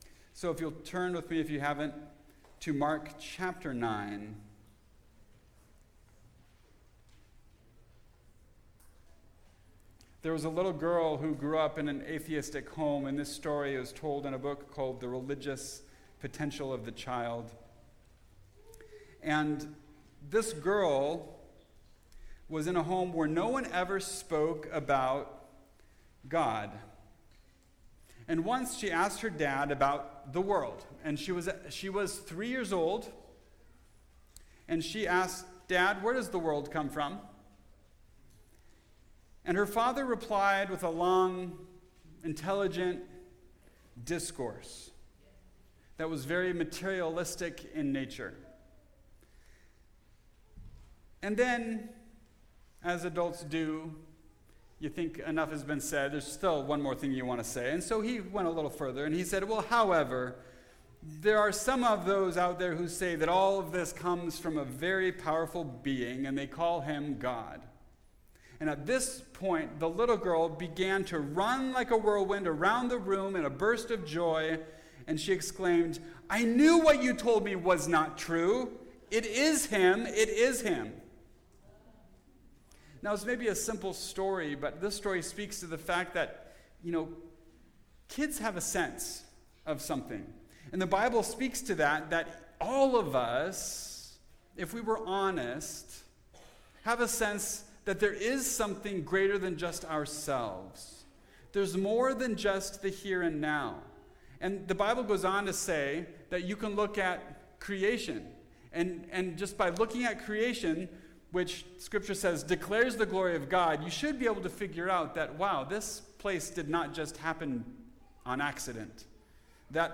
How To Be Great (Mark 9:30-50) – Mountain View Baptist Church